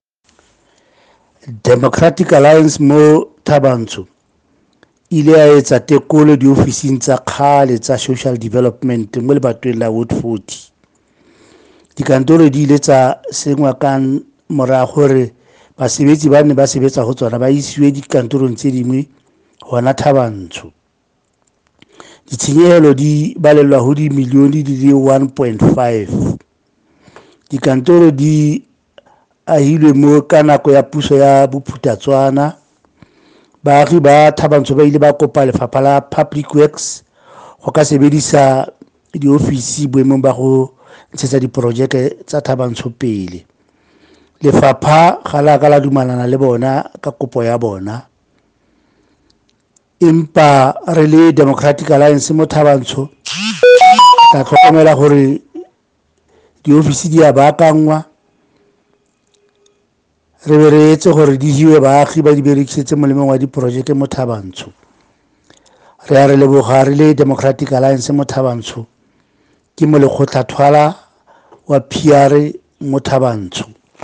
Sesotho soundbites by Cllr Zweli Thwala.